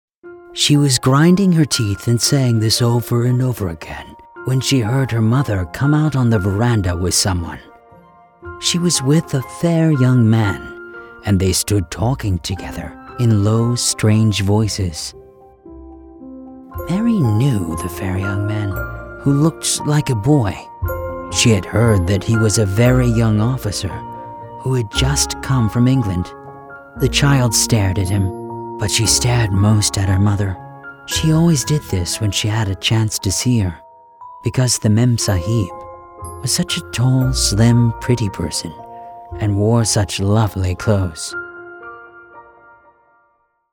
VOICE ACTOR DEMOS